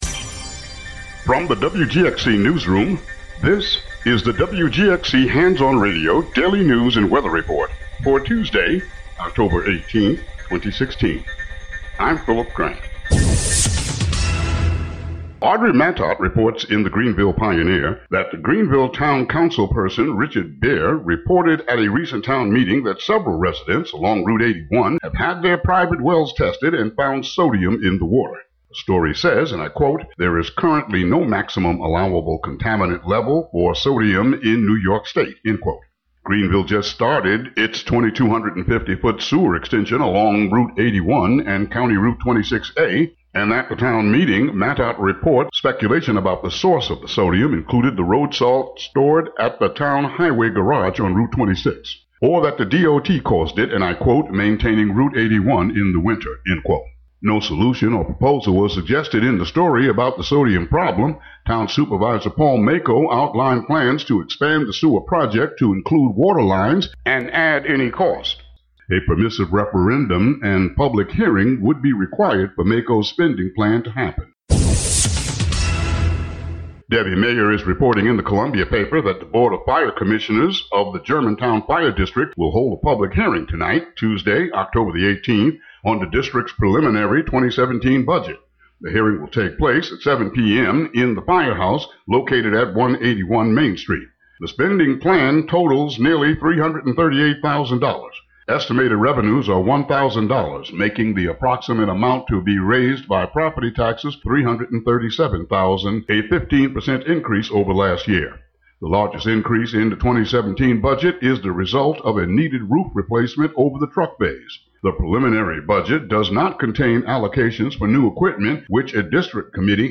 WGXC daily headlines and weather.